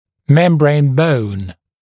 [‘membreɪn bəun][‘мэмбрэйн боун]покровная кость